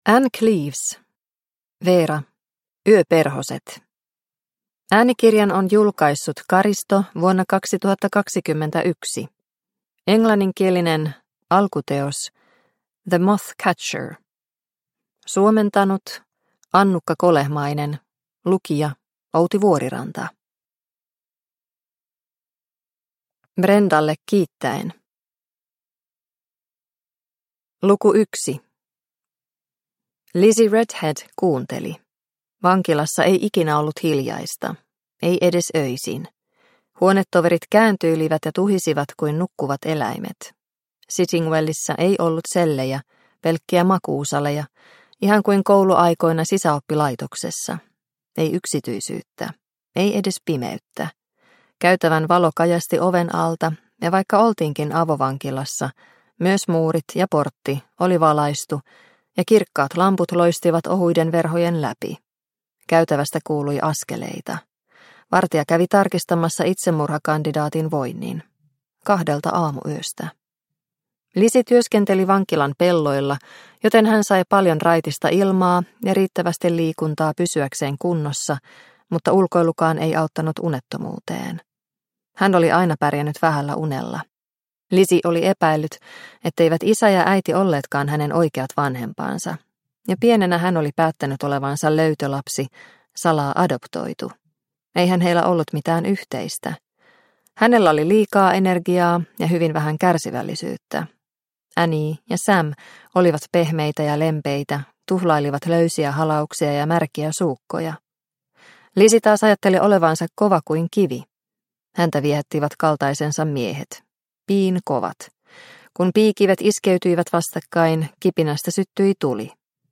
Yöperhoset – Ljudbok